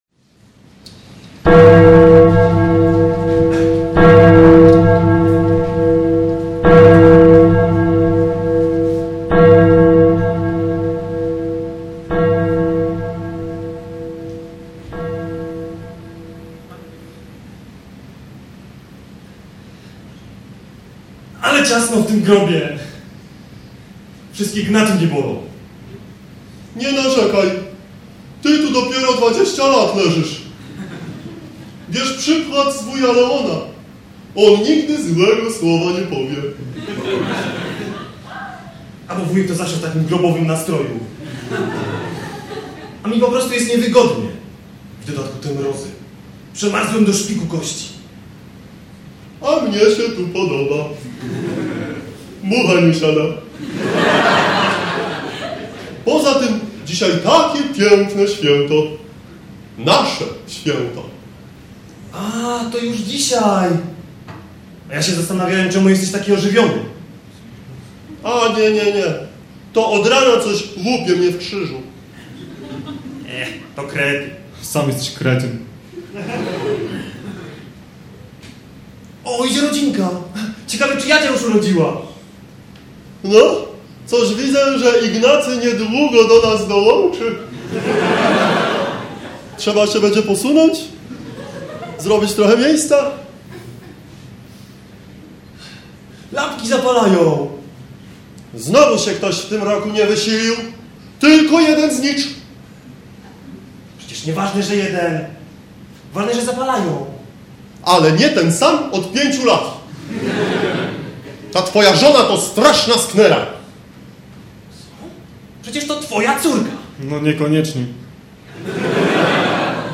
Skecz Święto Zmarłych (DKŚ - październik 2003)